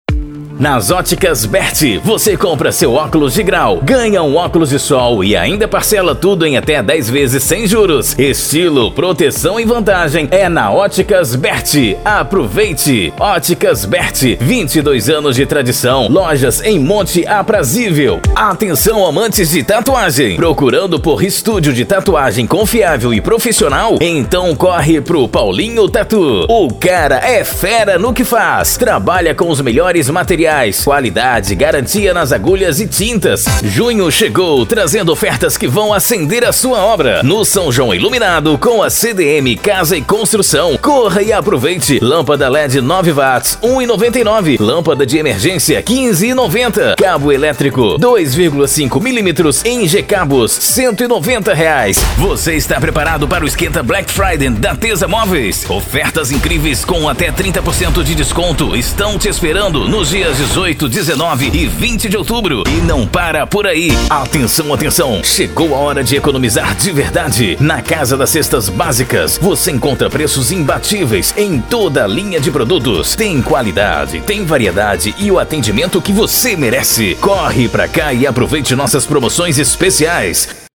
Spot Comercial
Vinhetas
Estilo(s):
Padrão
Impacto
Animada